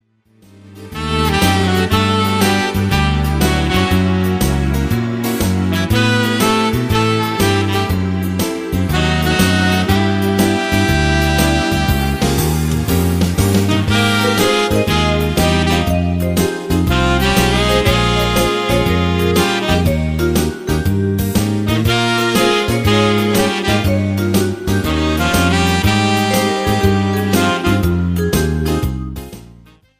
SWING  (4.02)